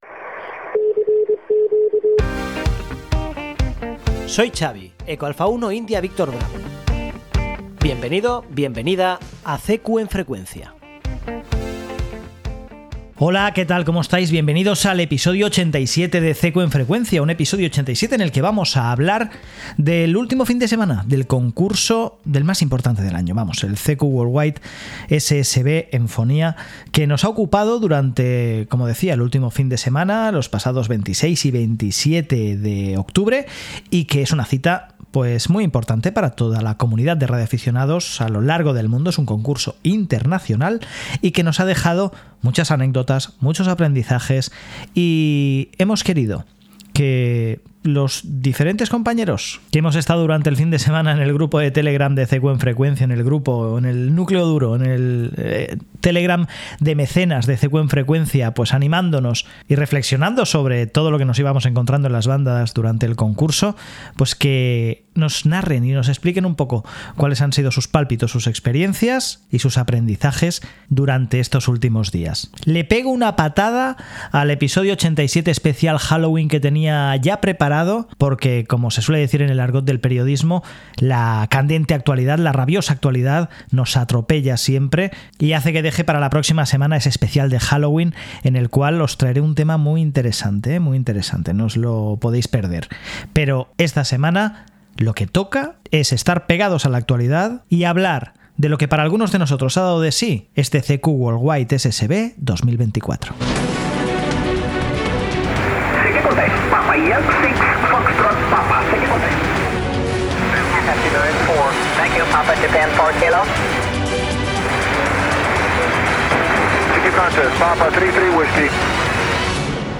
Esta semana en CQ en Frecuencia, nos adentramos en el intenso mundo del CQ World Wide SSB Contest, una de las competiciones más vibrantes y exigentes del año para los operadores de radio. Compartiré mis propias sensaciones y experiencias en la banda, así como algunos momentos clave del concurso, donde el desafío y la emoción estuvieron a flor de piel. Durante este episodio, escucharemos varios audios en los que el ambiente del concurso se vuelve casi palpable: transmisiones llenas de energía, intentos por capturar indicativos en medio del ruido, y la lucha constante contra la propagación cambiante y los retos técnicos.